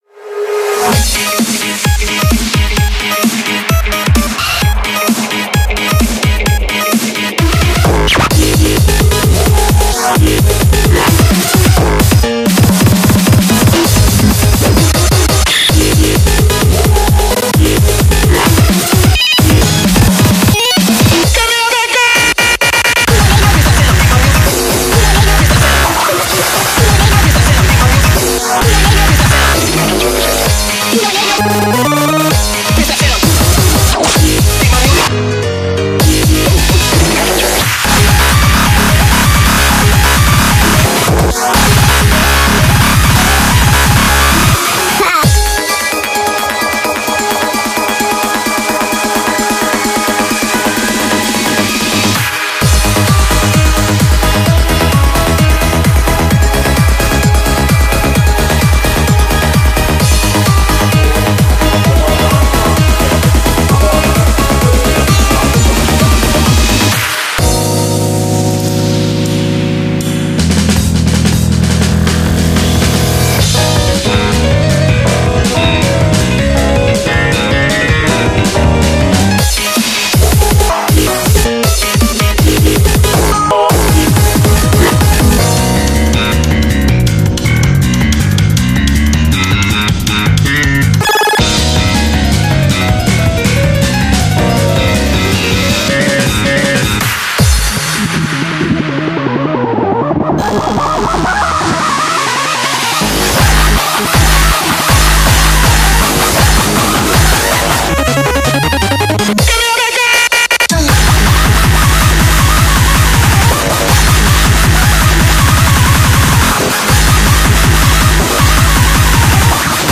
BPM65-640
Audio QualityPerfect (High Quality)
CommentsIt's a crazy song; LOTS of tempo changes